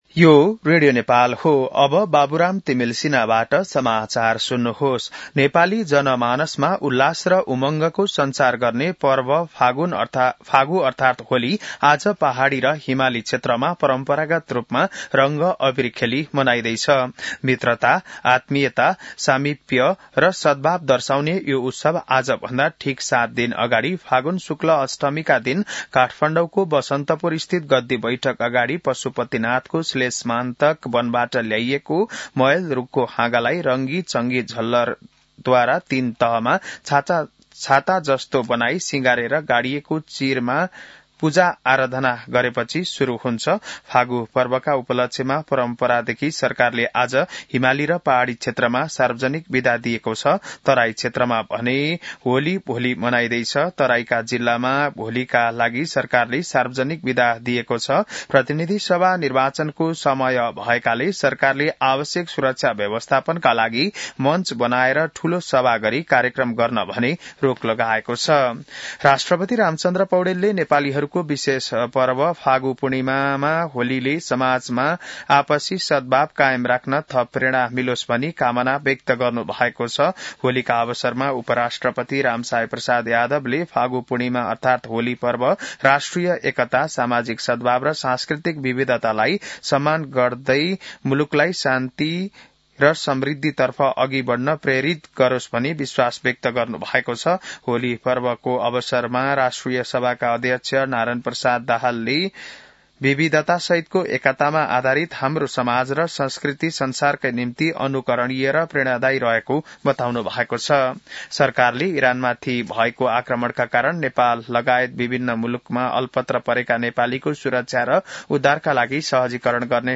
बिहान १० बजेको नेपाली समाचार : १८ फागुन , २०८२